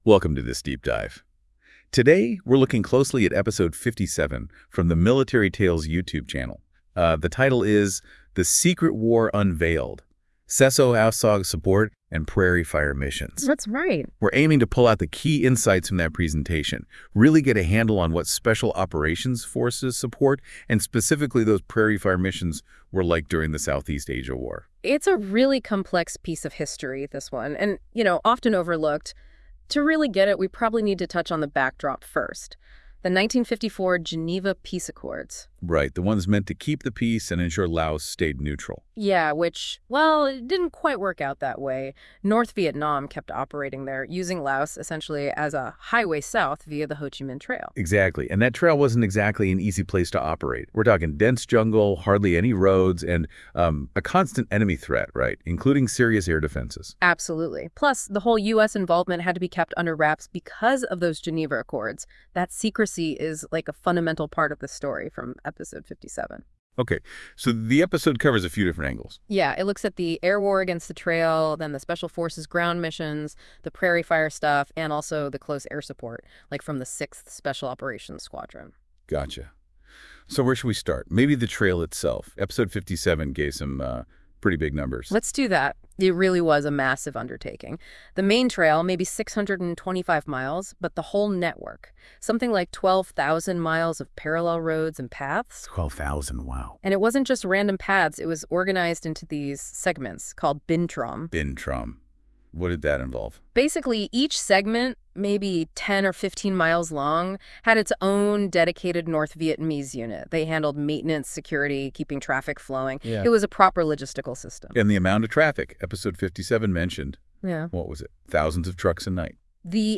Episode 57 of Military Tales pulls back the curtain on one of the most secretive and dangerous aspects of the Vietnam War: the fight against the Ho Chi Minh Trail and the unique partnership between MACV-SOG Special Forces on the ground and their dedicated Air Force support. This episode features personal accounts from individuals who were at the heart of this hidden conflict.